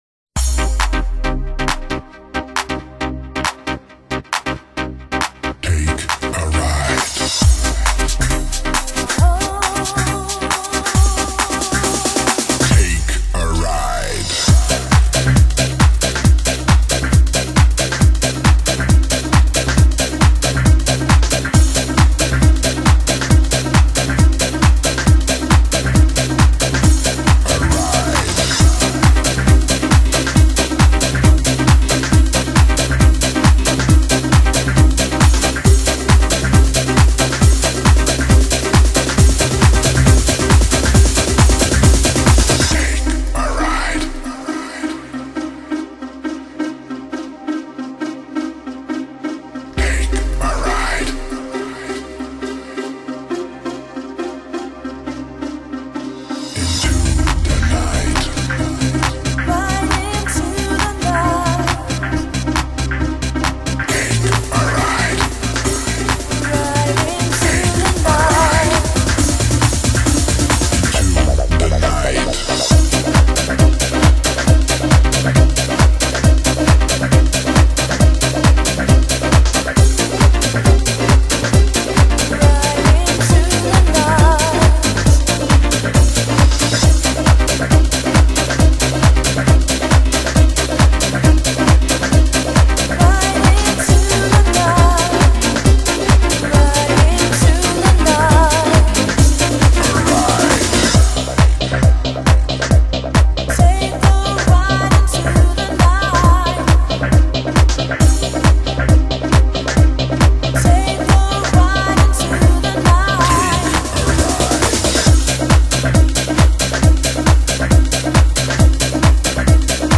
Genre: Dance, Club